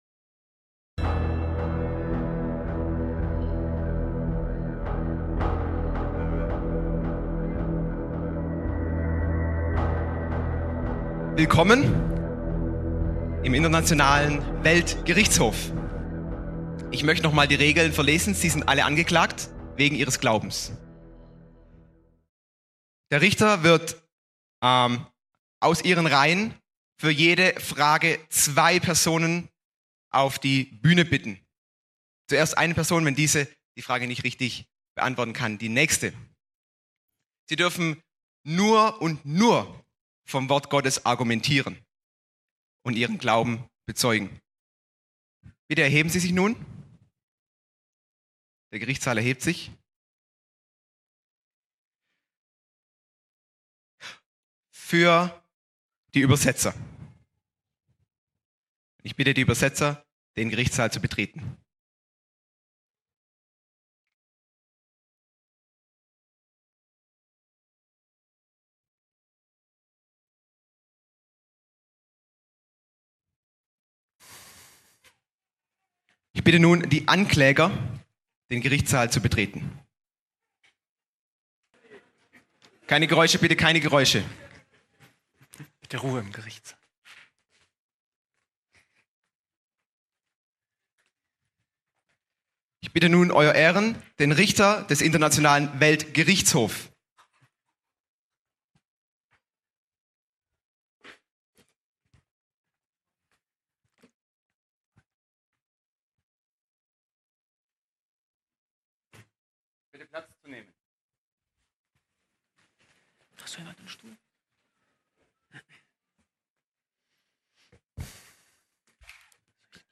Auf der Anklagebank schildert eine Gerichtsverhandlung, komplett mit Richter, Staatsanwälten und Angeklagten (dem Publikum). Verschiedene Fälle werden durchgespielt, populäre Vorwürfe geäußert, Glaubenspunkte biblisch verteidigt.